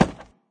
woodgrass3.ogg